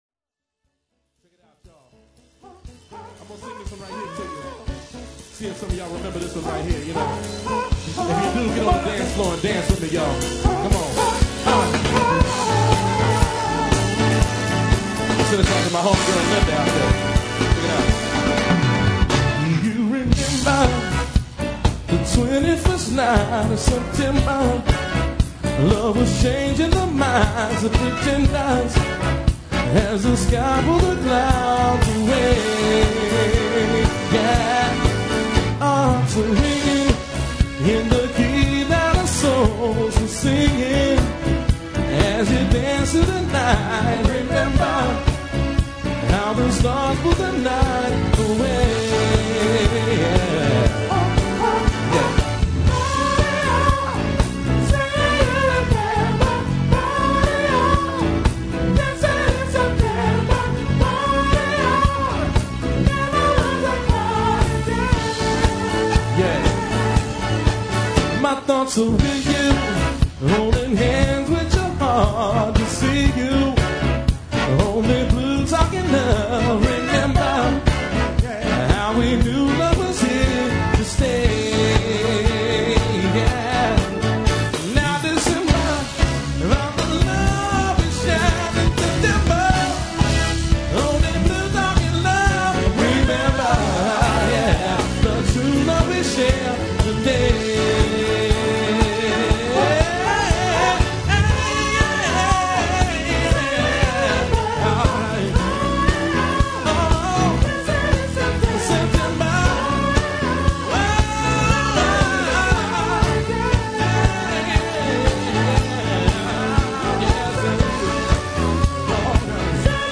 funk and R&B